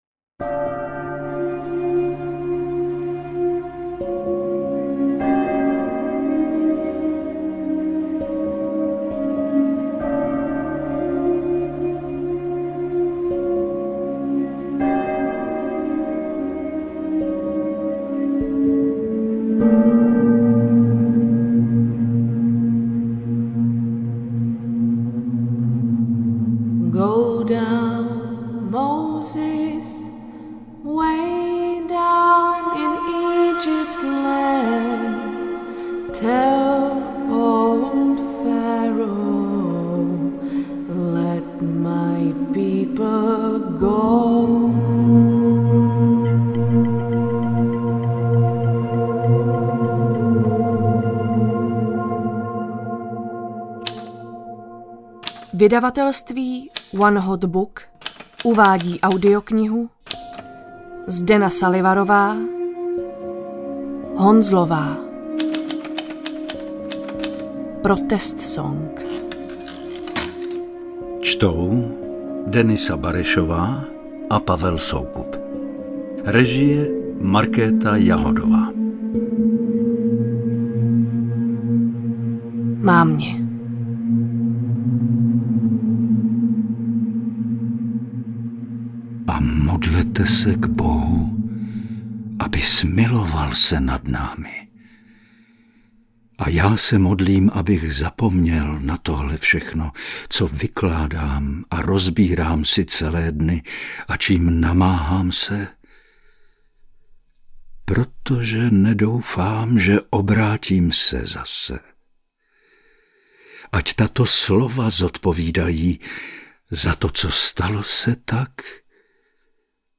Honzlová audiokniha
Ukázka z knihy